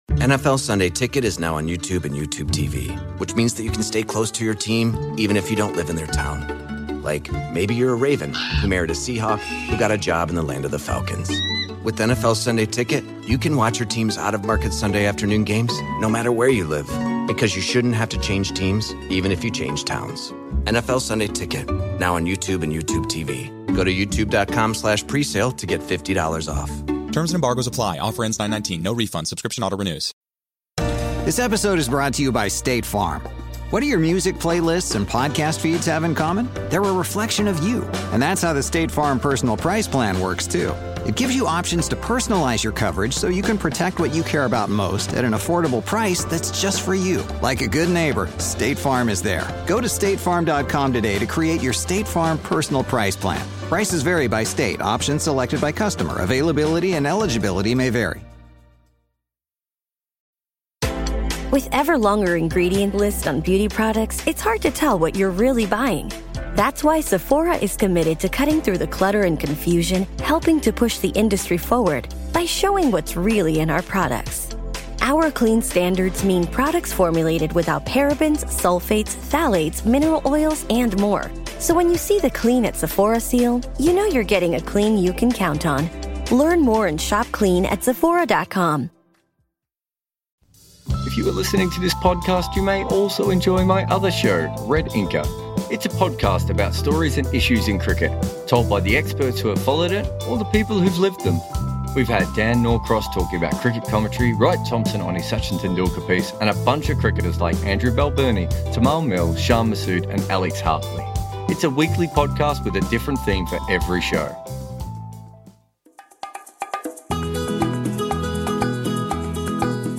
Clips And Music used in this episode: Royalty free Music